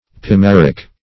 Search Result for " pimaric" : The Collaborative International Dictionary of English v.0.48: Pimaric \Pi*mar"ic\, a. [NL. pinum maritima, an old name for Pinum Pinaster , a pine which yields galipot.]
pimaric.mp3